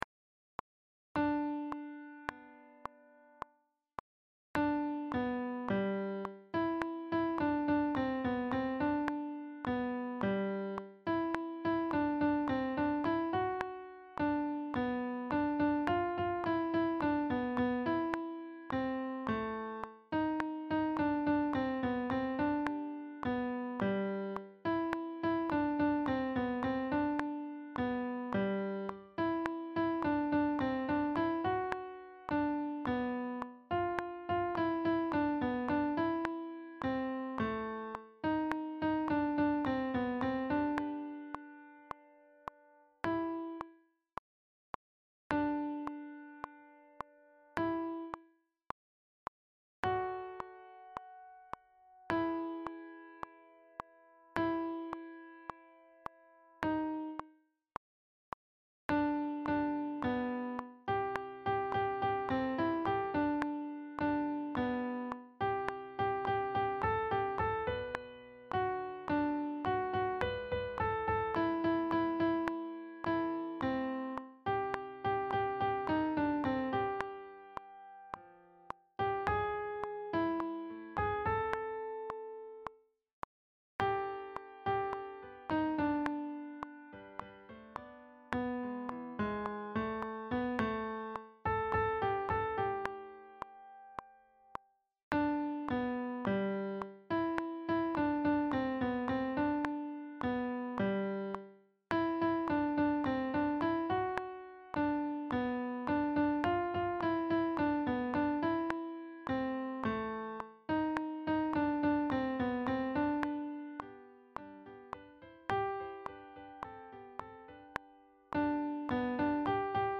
Coeur de Soldat Alto
Coeur_de_soldat_Alto.mp3